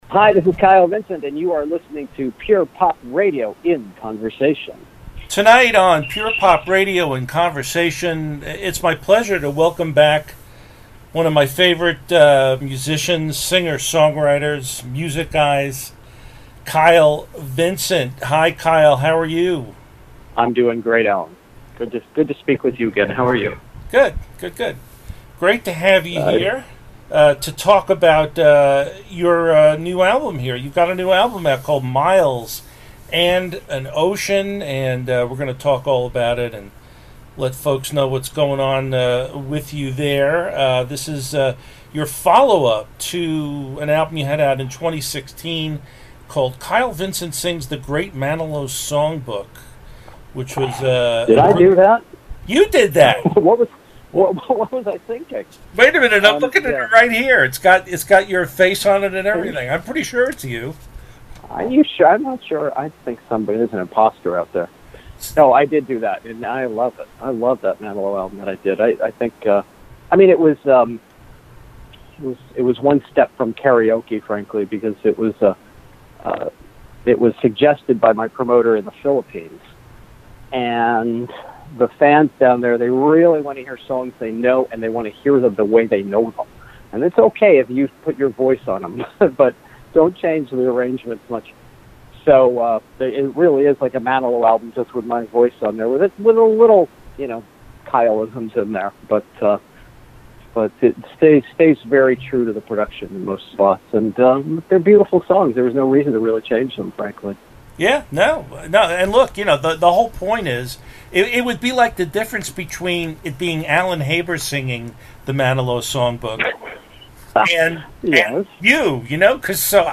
(This interview is presented in scoped format; the songs have been removed due to copyright concerns.)